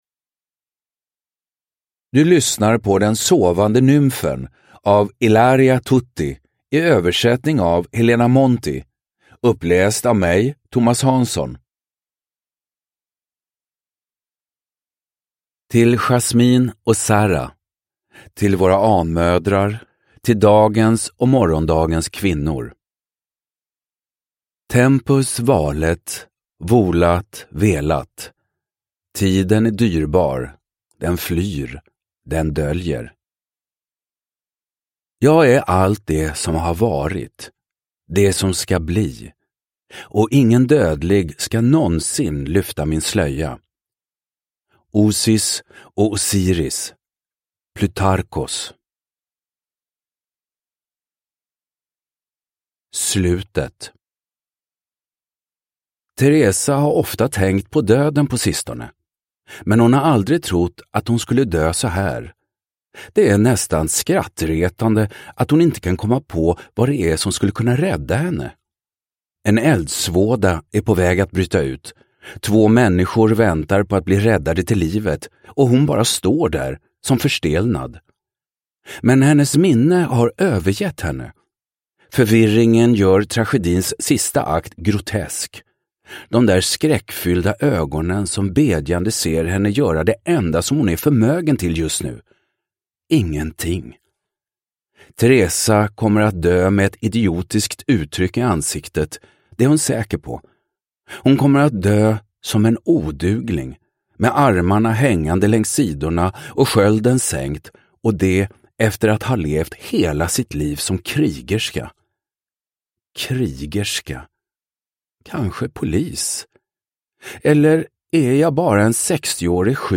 Den sovande nymfen – Ljudbok – Laddas ner
Uppläsare: Thomas Hanzon